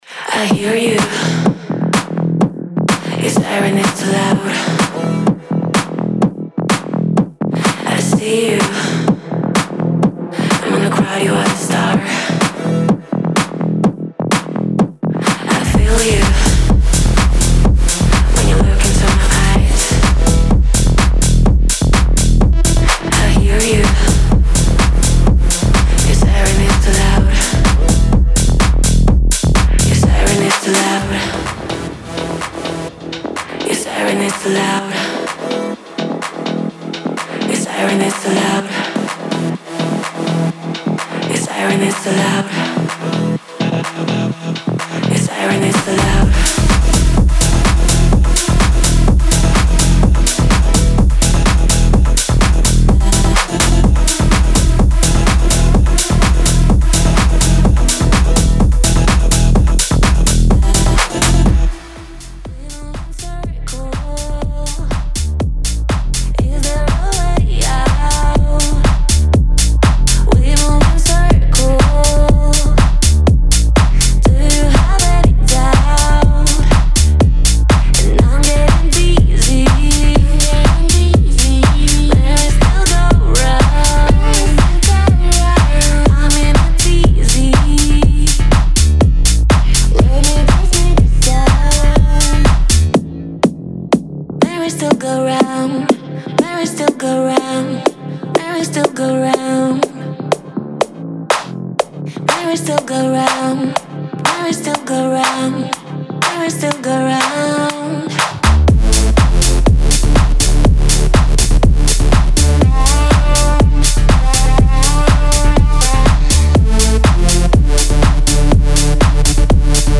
Genre:Tech House
デモサウンドはコチラ↓